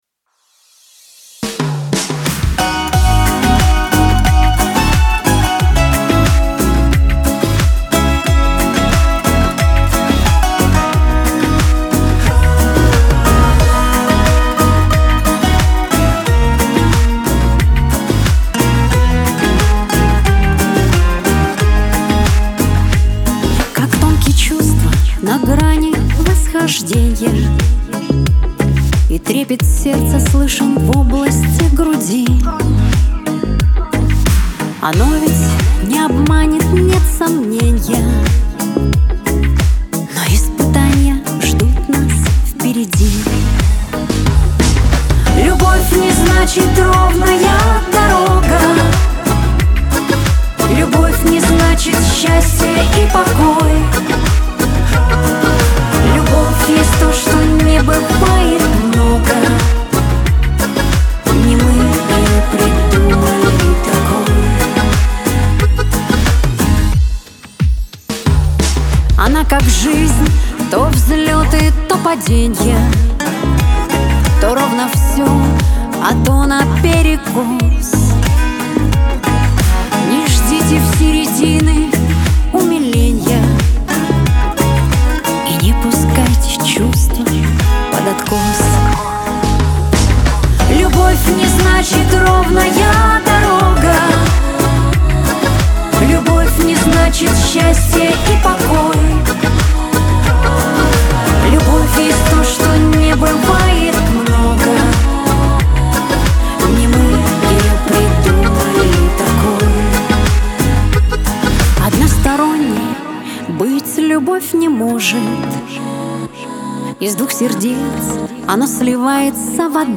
Трек размещён в разделе Русские песни / Шансон / 2022.